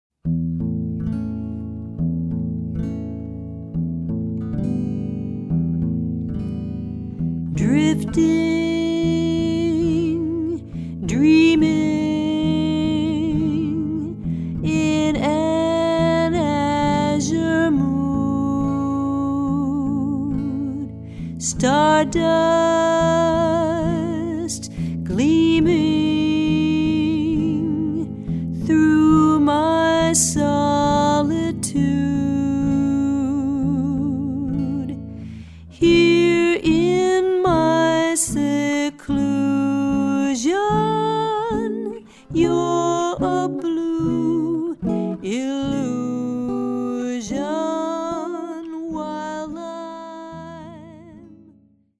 vocals, guitar
trumpet, flugelhorn
piano
bass
drums